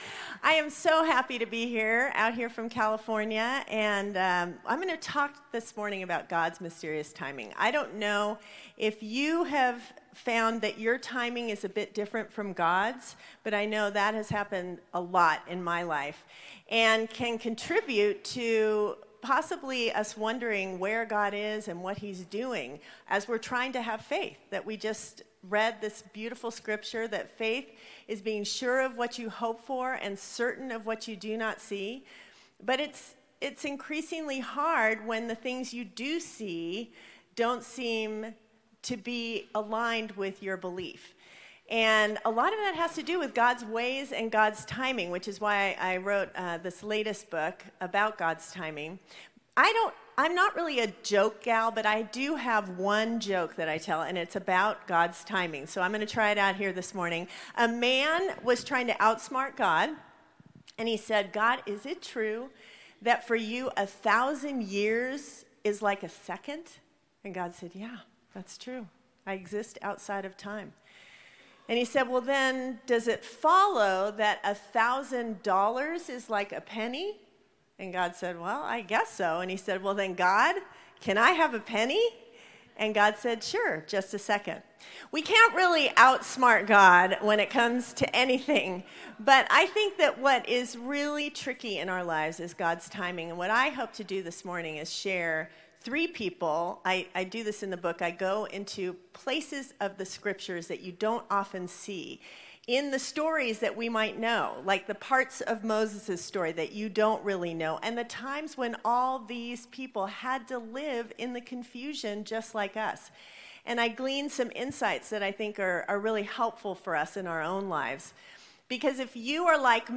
Sermon Audio Archive